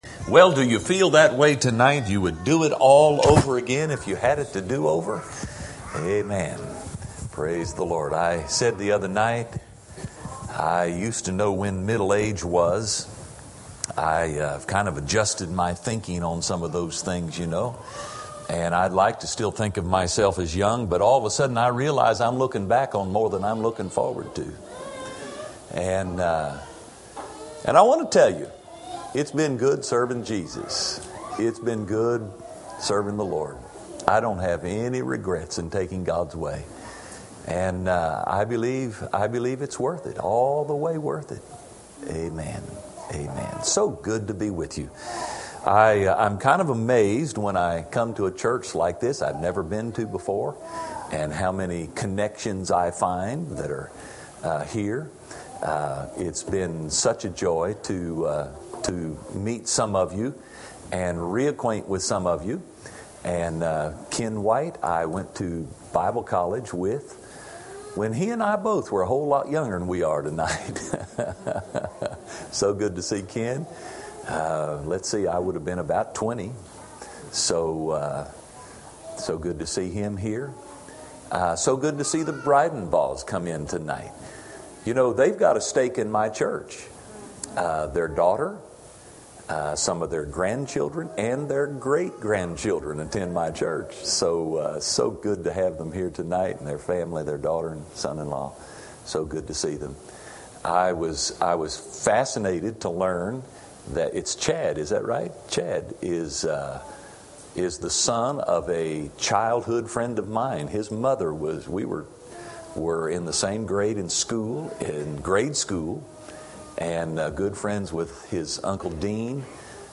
A sermon
Series: Spring Revival 2016 Tagged with ark , faith , grace , noah